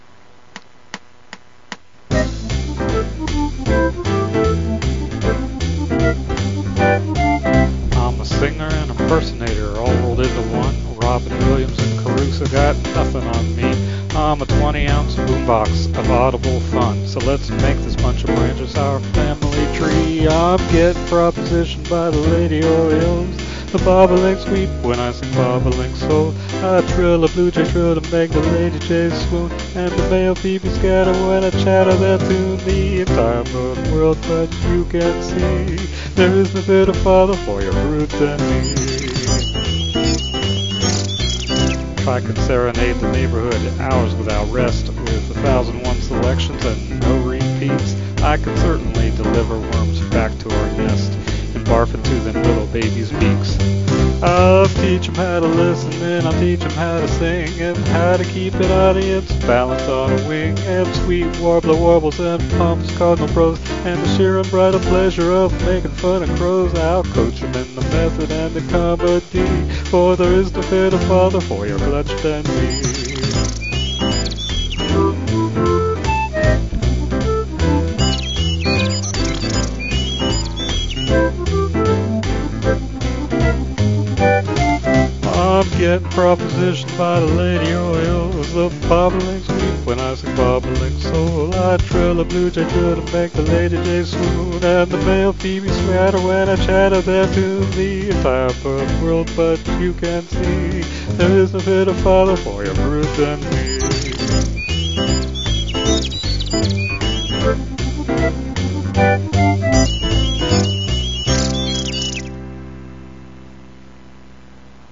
spoken 4/4, male voice